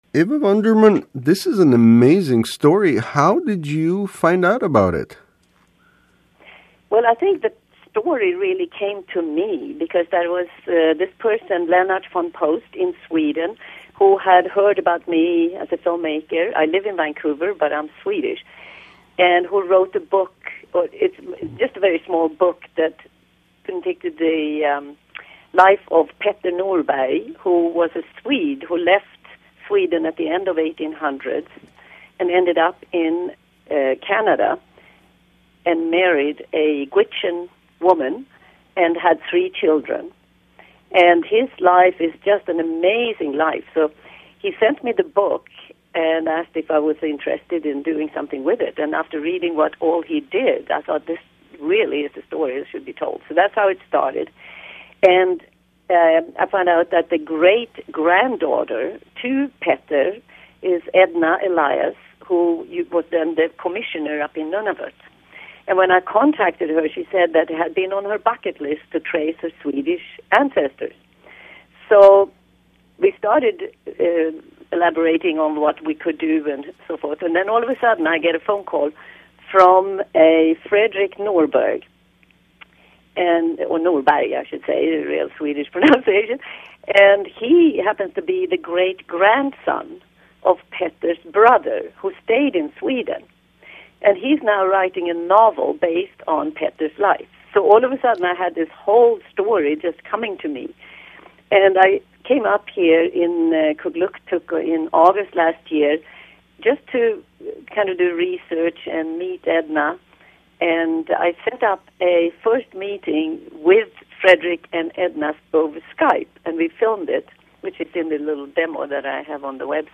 Speaking on the phone from Kugluktuk